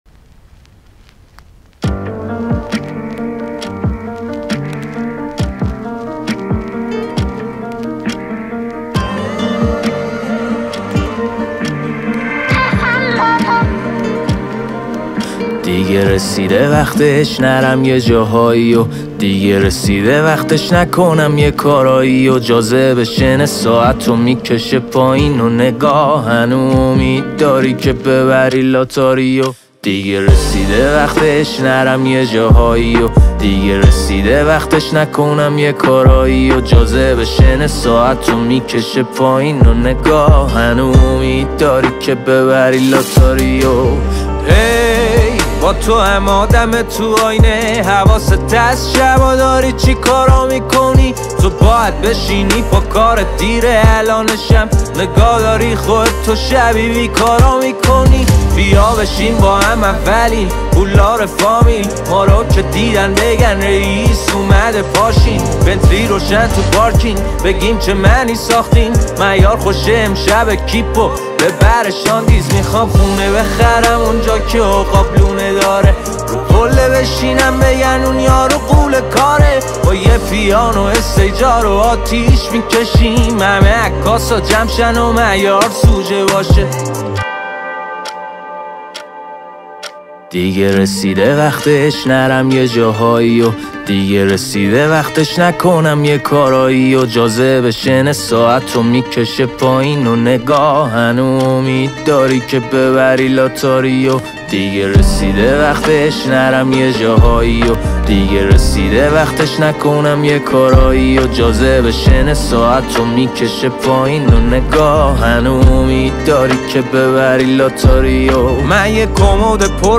• موسیقی رپ ایرانی